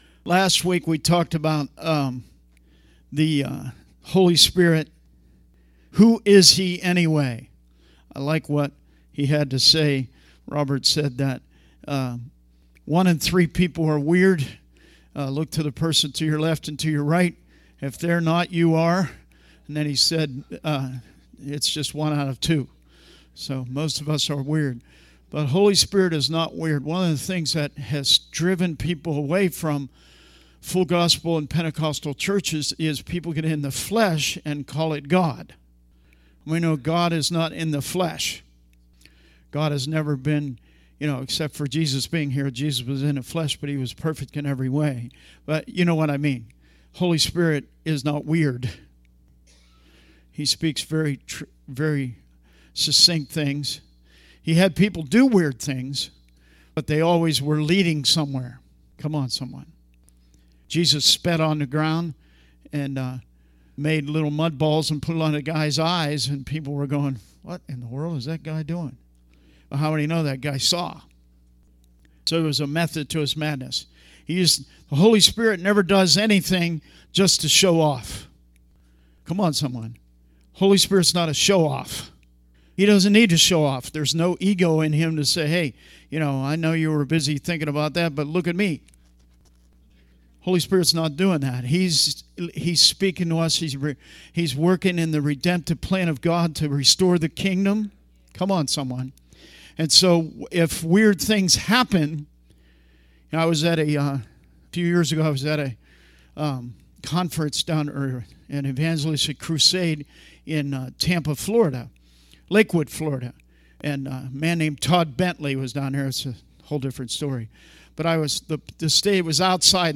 Service Type: Wednesday Teaching